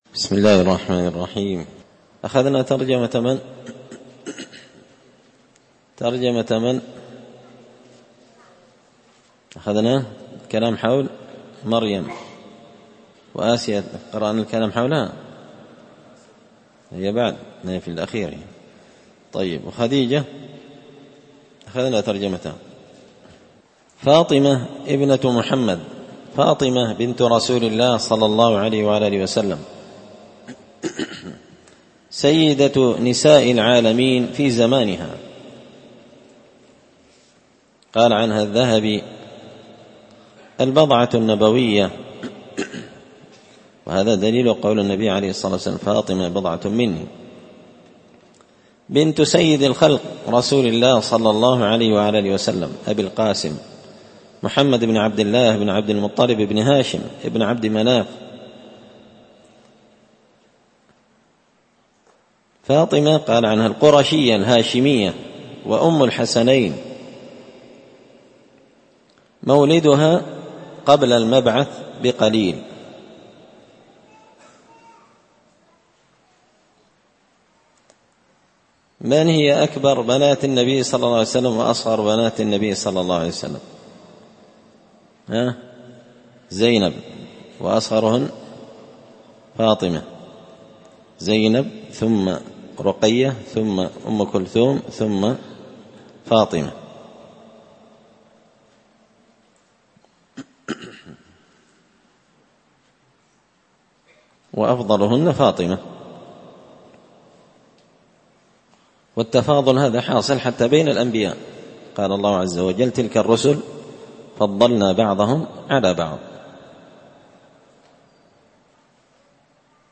كتاب العشرينيات في عقيدة أهل السنة والأثر- الدرس 208
مسجد الفرقان قشن_المهرة_اليمن